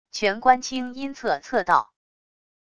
全冠清阴测测道wav音频